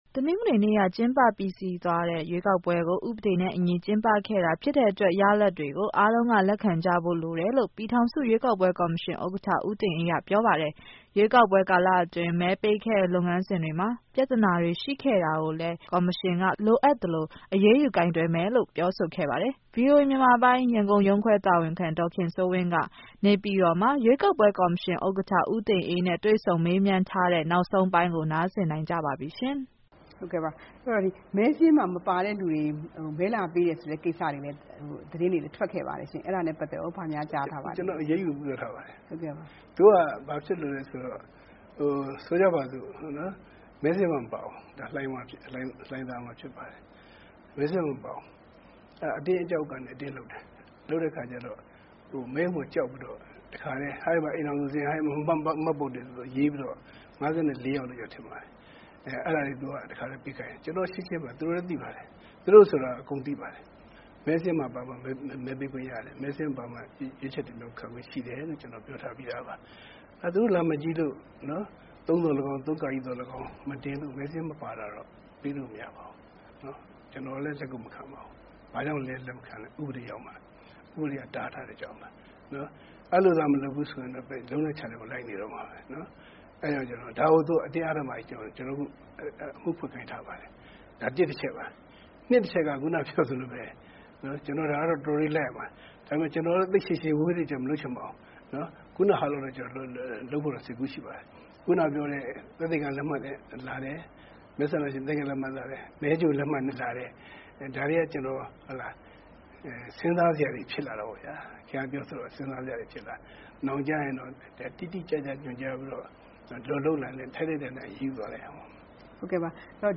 ကော်မရှင်ဥက္ကဌ ဦးတင်အေးနဲ့ ဗွီအိုအေတွေ့ဆုံမေးမြန်းချက် (အပိုင်း-၂)